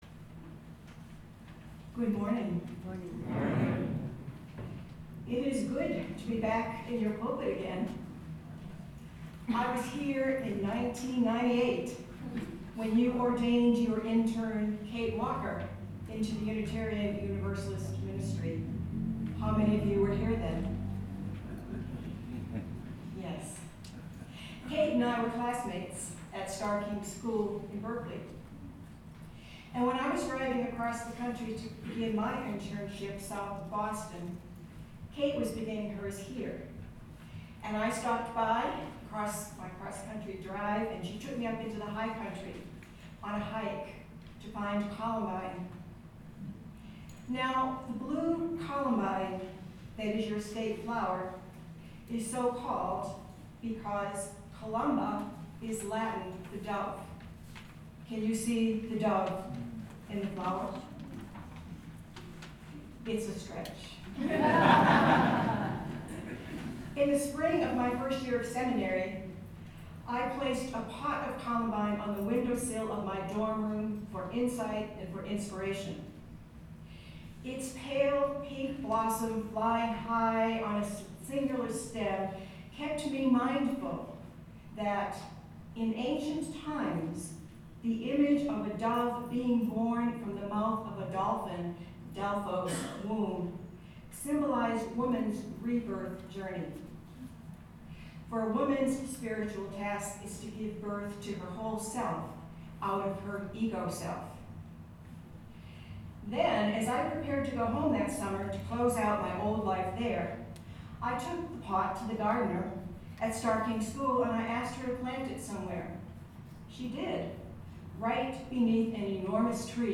First Universalist, Denver, CO 5 July 2015
75sermonthespiritofamericaandthechallengesofclimatechange.mp3